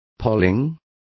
Complete with pronunciation of the translation of polling.